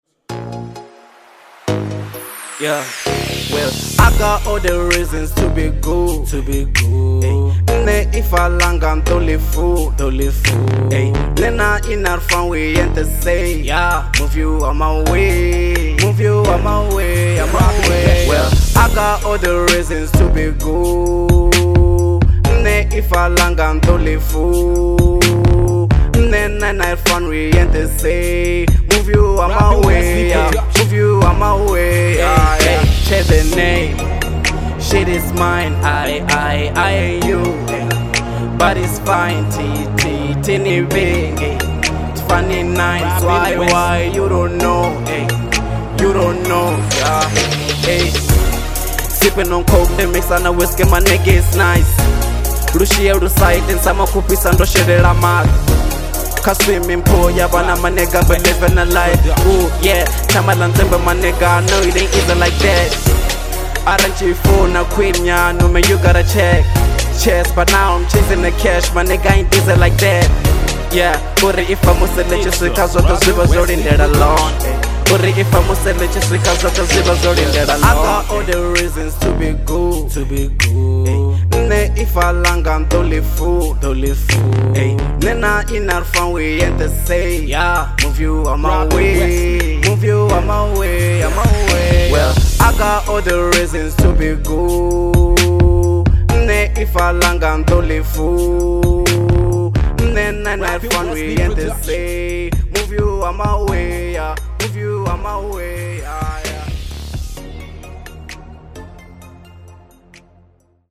01:50 Genre : Venrap Size